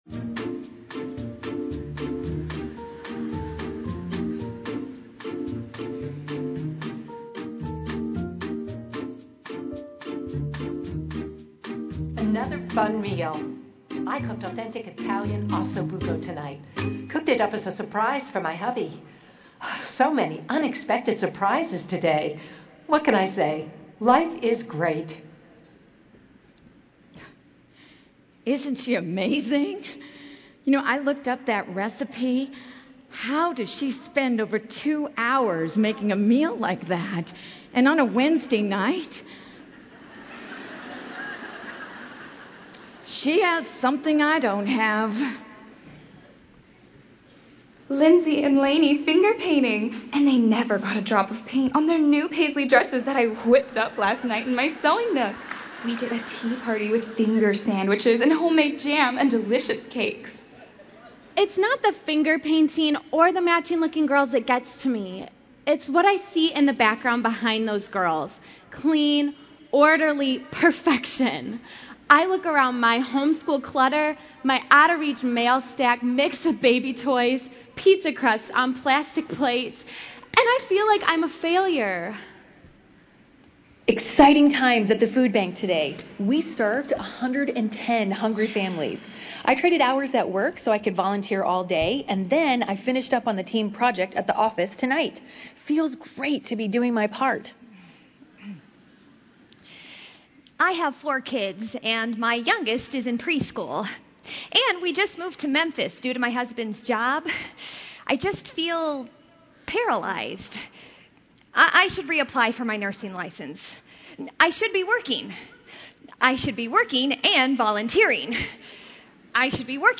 Session 3 (drama): Life Based on Instagram